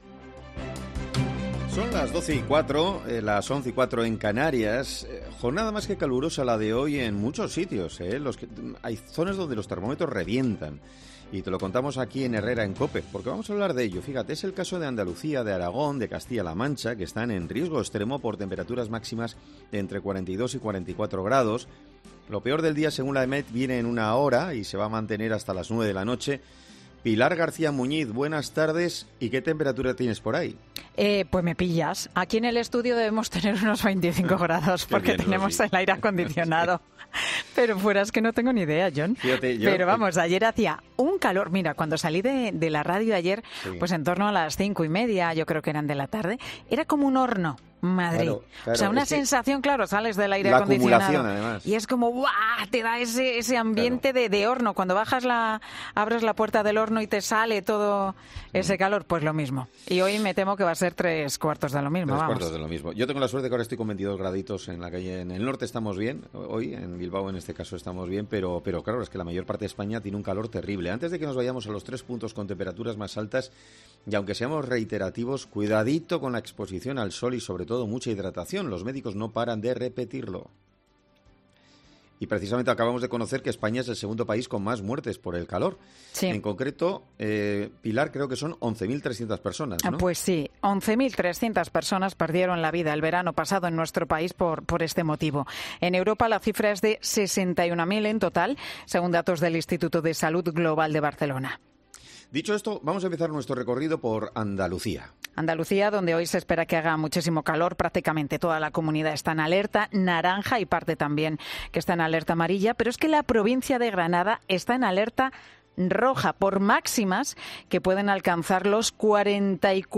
En 'Herrera en COPE' hablamos con una experta que explica cuáles son los síntomas de un golpe de calor